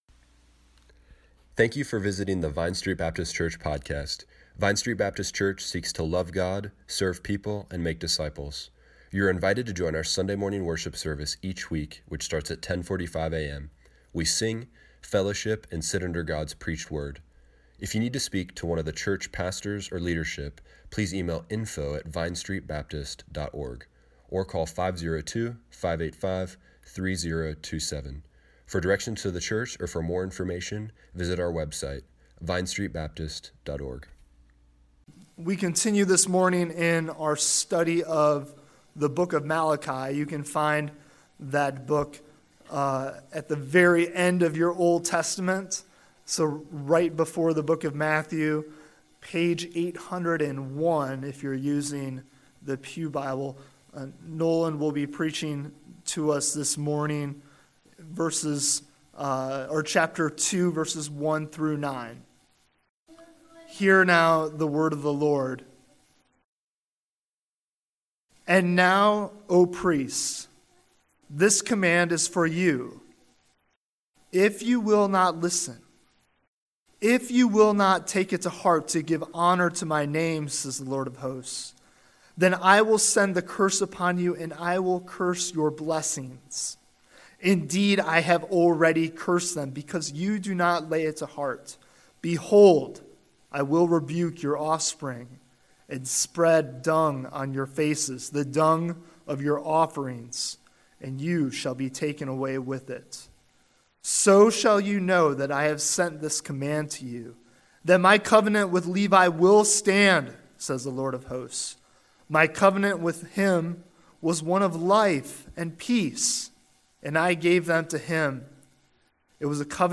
Service Morning Worship
sermon